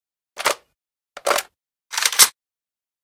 abakan_reload.ogg